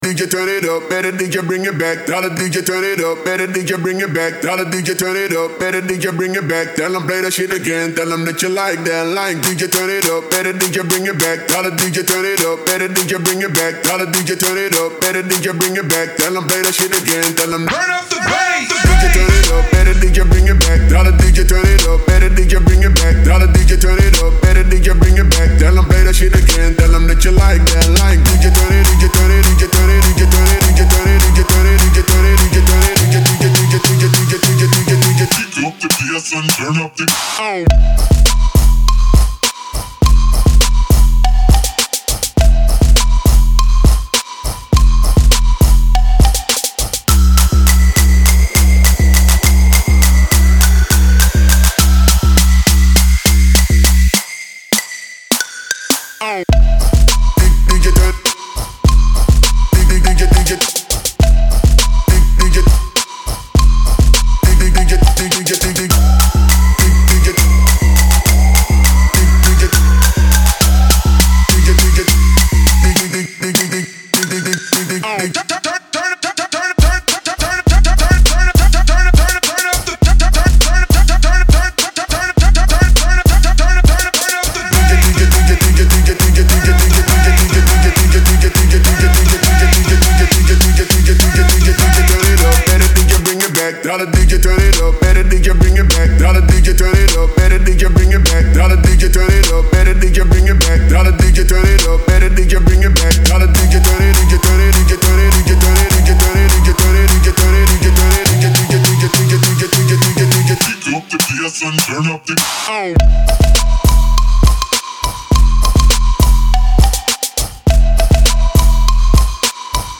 TRAP в МАШИНУ